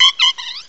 cry_not_audino.aif